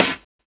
Snare set 2 006.wav